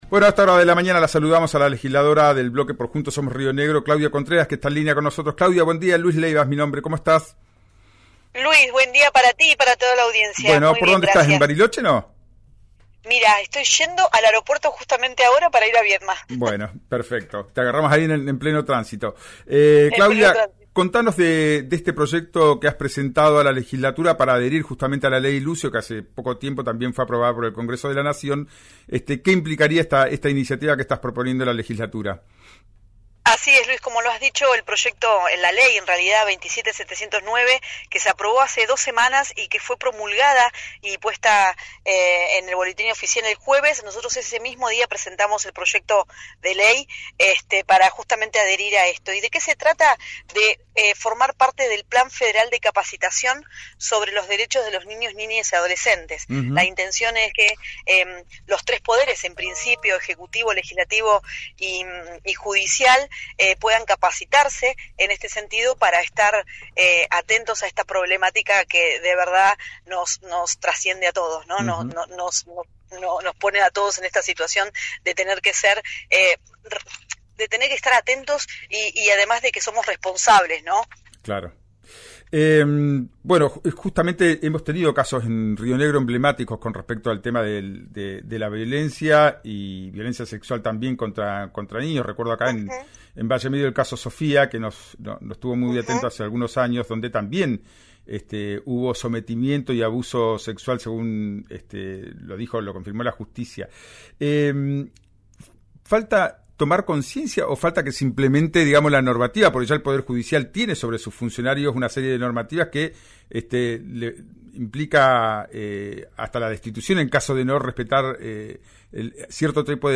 En diálogo con «Ya es tiempo» por RÍO NEGRO RADIO, Contreras explicó los alcances de la iniciativa que impulsa y por qué es importante para la provincia avanzar en este sentido.
Escuchá a la legisladora provincial Claudia Contreras en “Ya es tiempo”, por RÍO NEGRO RADIO.